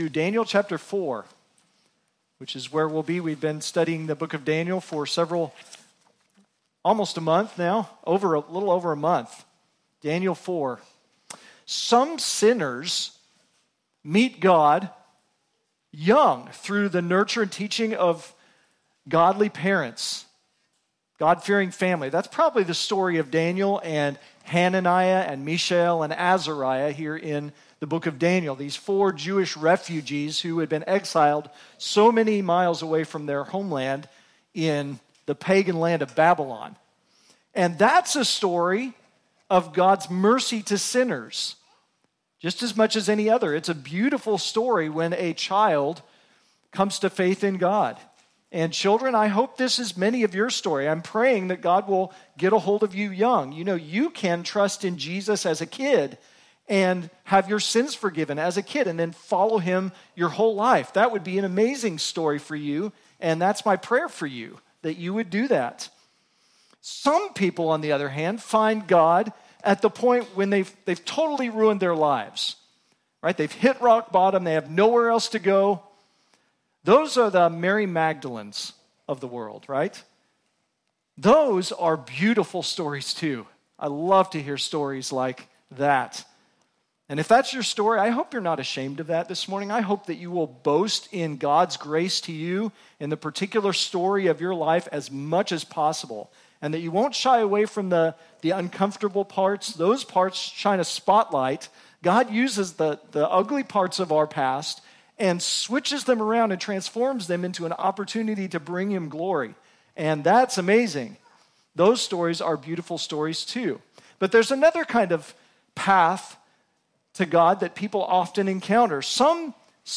Sermons | Redemption Bible Church